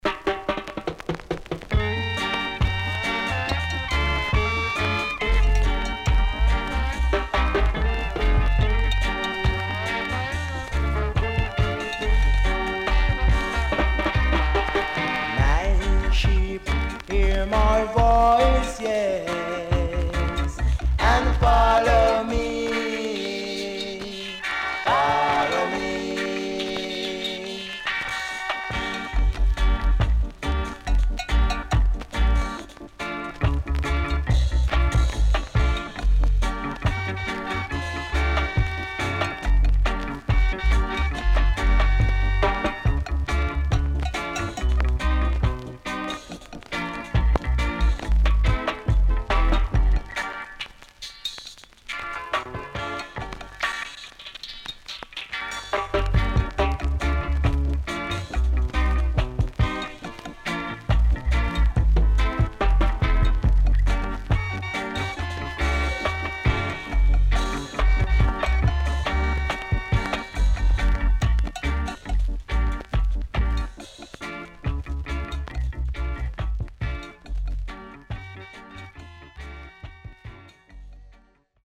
Great Killer Roots & Dubwise
SIDE A:所々ノイズ入ります。